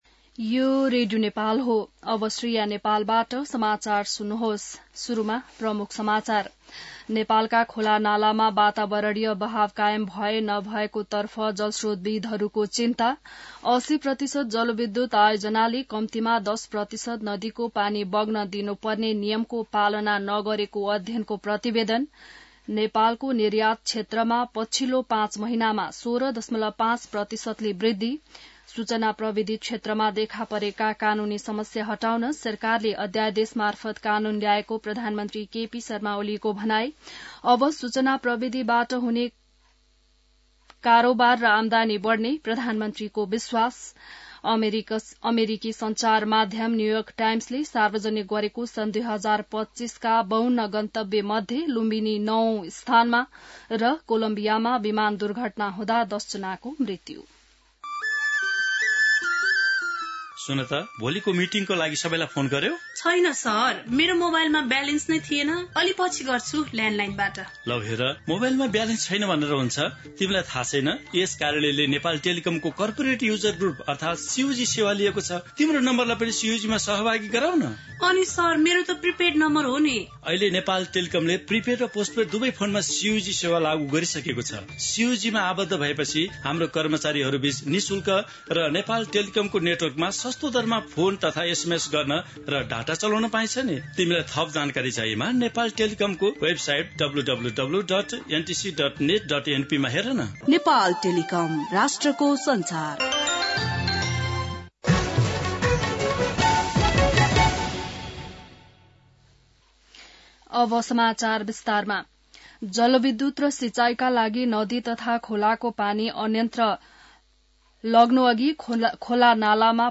बिहान ७ बजेको नेपाली समाचार : २९ पुष , २०८१